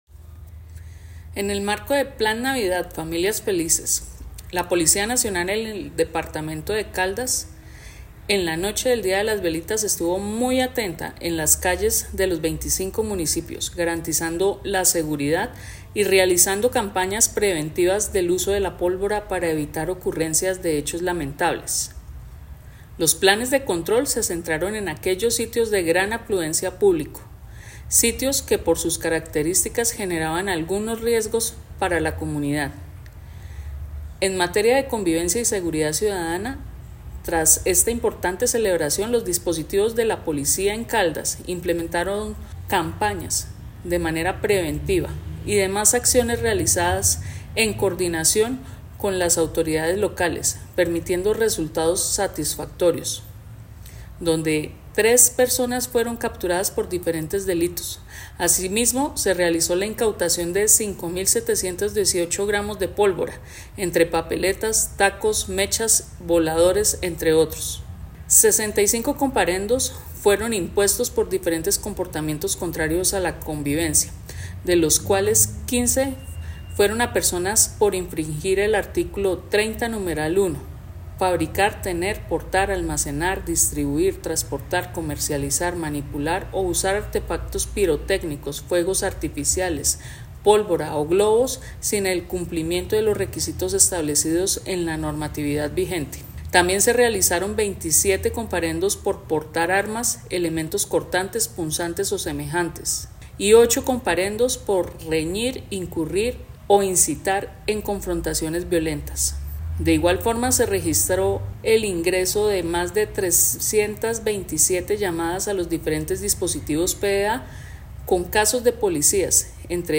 Coronel Liliana Andrea Morales Falla, comandante de la Policía de Caldas